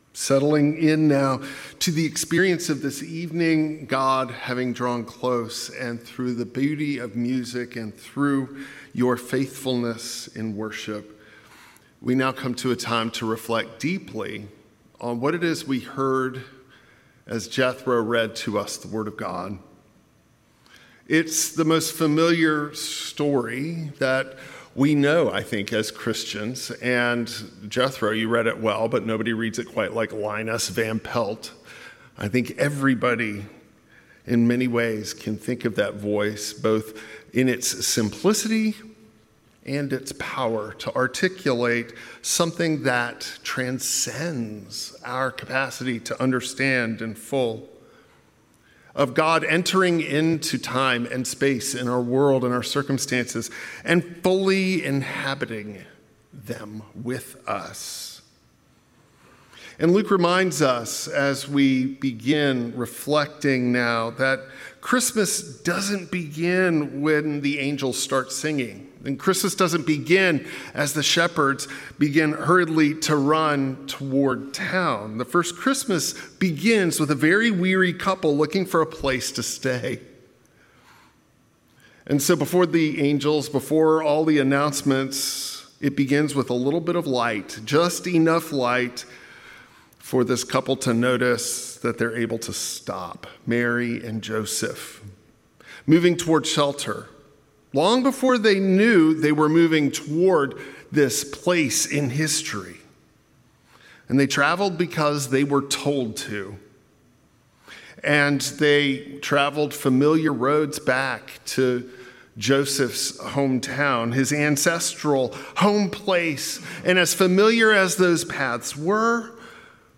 A Christmas Eve sermon on Luke’s nativity story, weary travelers, open doors, and finding rest in Christ along the long road home.